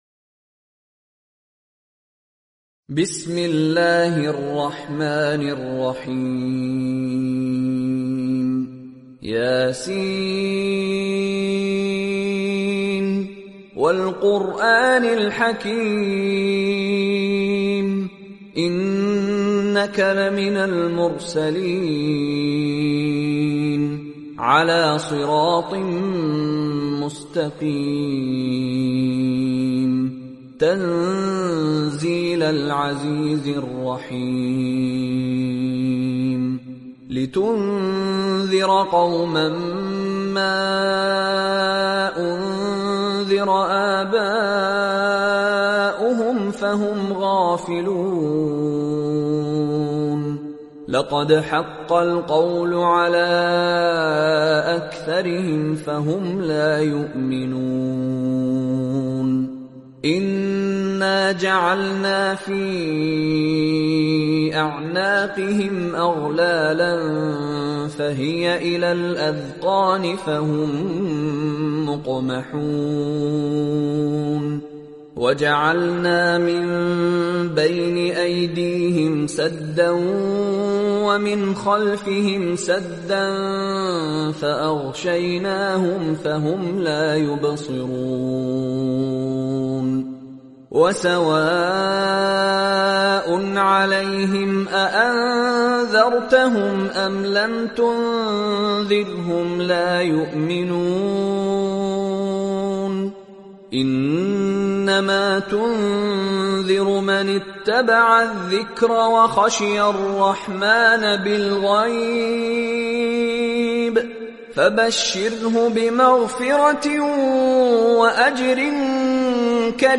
Listen online to the Tilawat of Surah Yaseen in the emotional voice of renowned Qari Mishary.
Listen to the soft and emotional recitation of Surah Yaseen by Mishary Rashid Alafasy in high-quality MP3 audio. His gentle and soothing voice brings peace to the heart and calms the soul.
Surah-Yaseen-Complete-Beautiful-Recitation-Mishary-Rashid-Al-Afasy-Quran-36-64.mp3